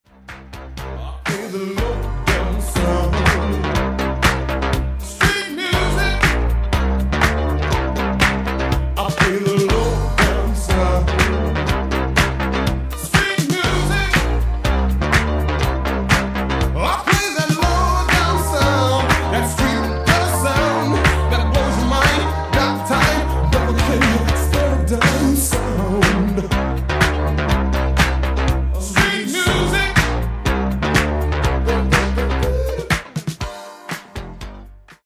Genere:   Disco | Soul | Funk